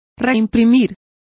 Complete with pronunciation of the translation of reprinting.